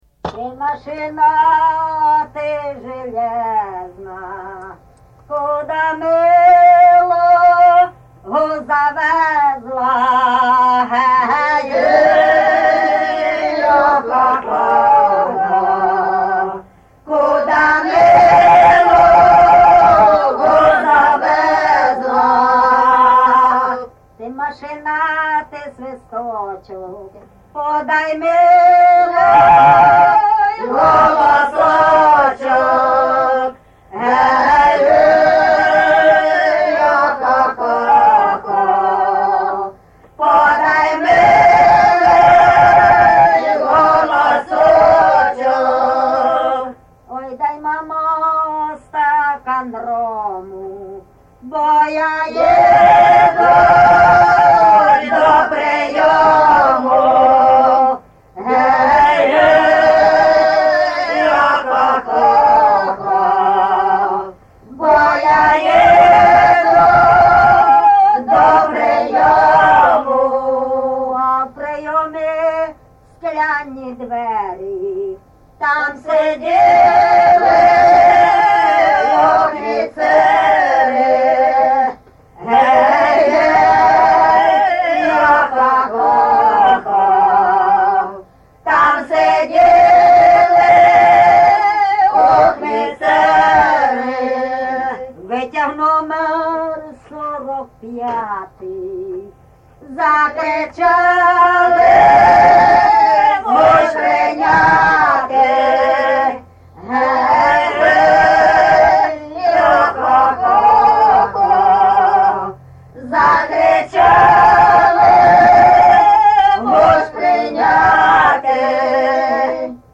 ЖанрСолдатські